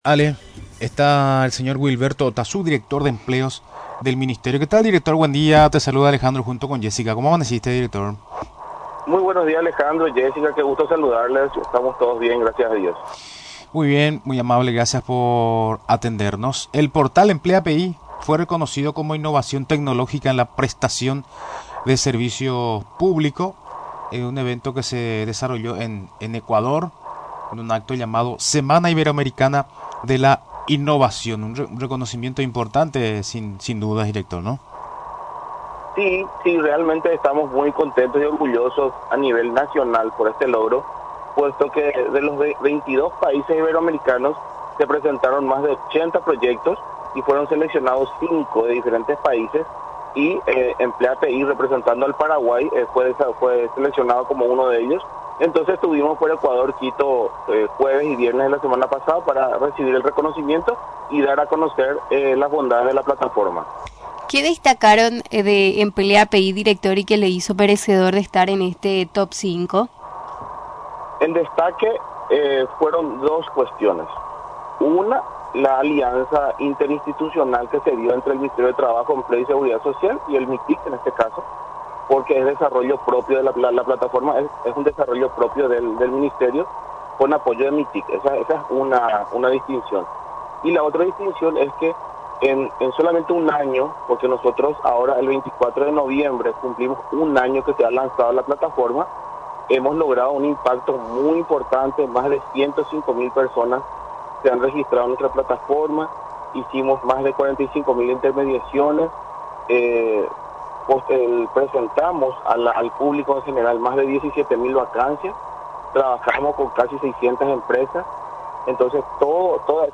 Durante la entrevista en Radio Nacional del Paraguay, explicó los detalles sobre el galardón recibido.